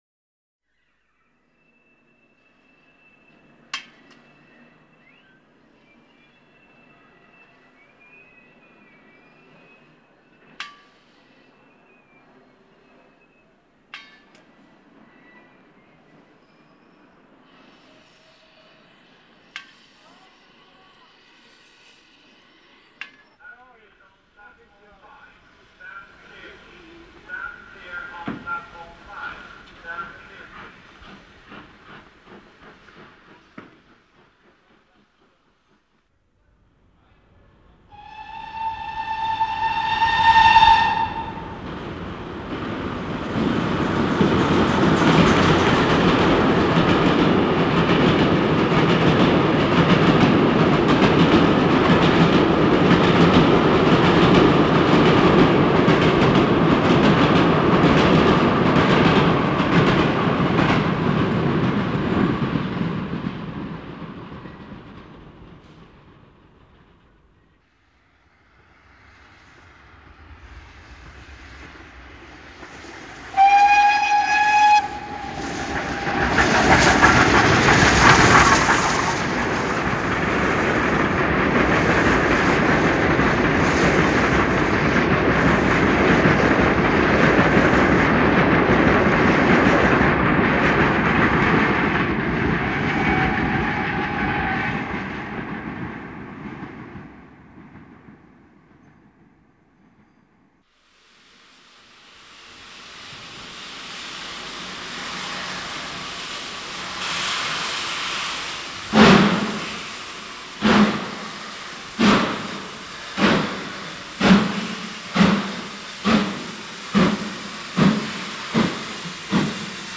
5028 features at the beginning of the clip.
At Exeter, St. Davids Station July 1957.wma